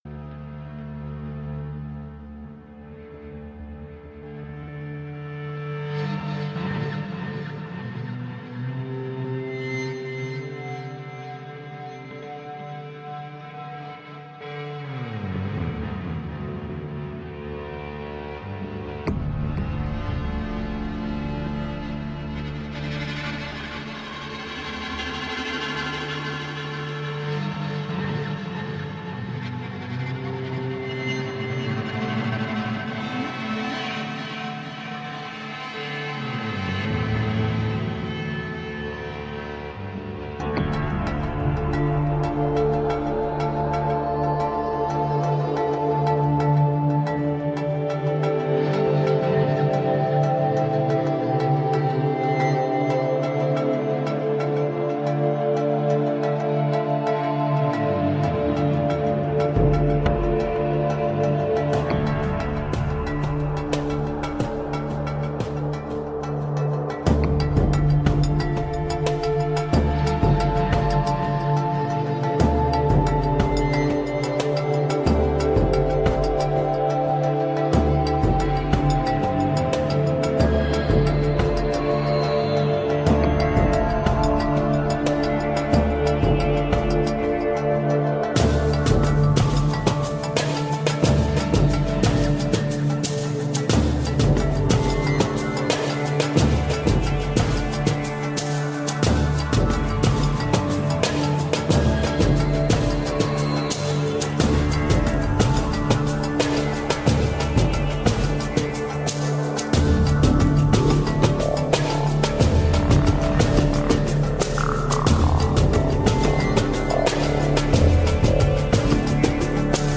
Spiritual new age for today's world.
Tagged as: World, New Age